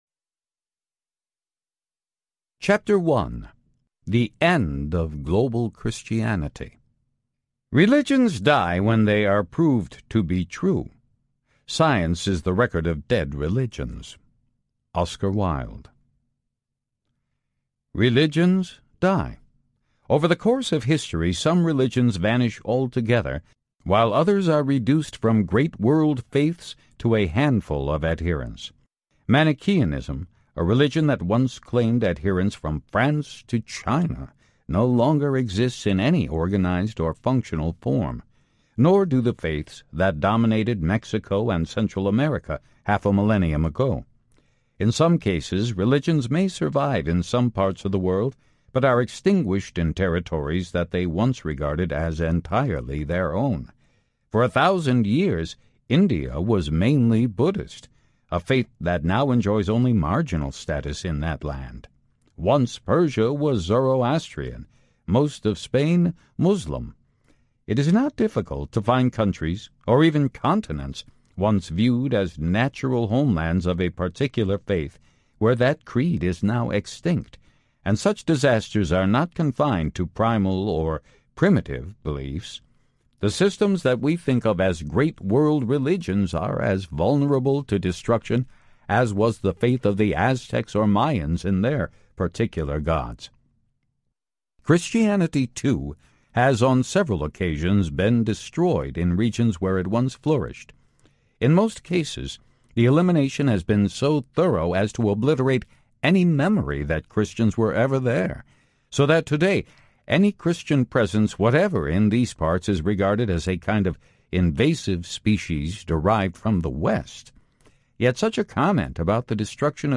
The Lost History of Christianity Audiobook